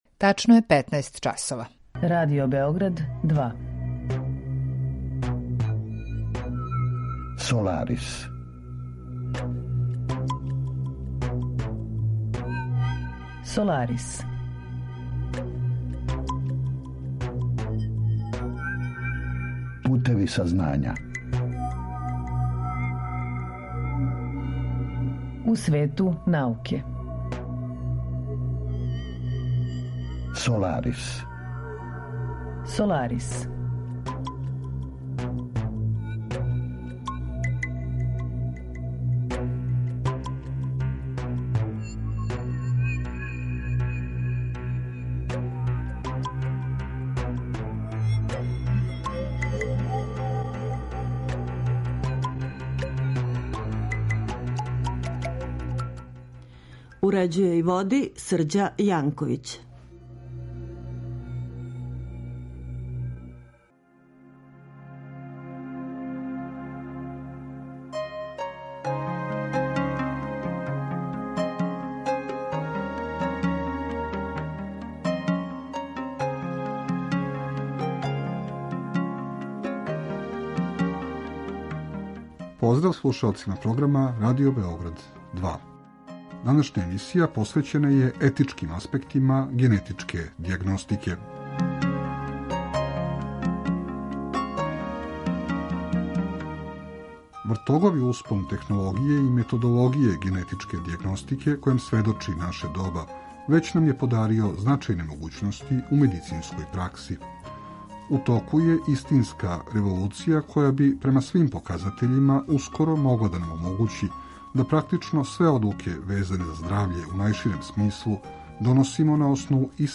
Саговорници у емисији обухватају научнике који се баве истраживањима из различитих области, од носилаца врхунских резултата и признања до оних који се налазе на почетку свог научног трагања, али и припаднике разноврсних професија који су у прилици да понуде релевантна мишљења о одговарајућим аспектима научних подухвата и науке у целини.